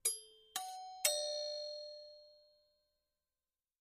Door Bells; Household Door Bells 2